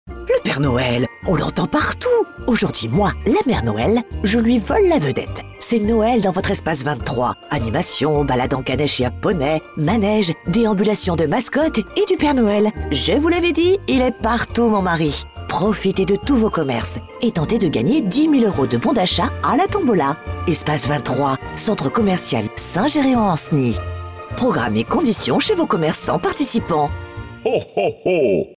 IMITATION ET TOON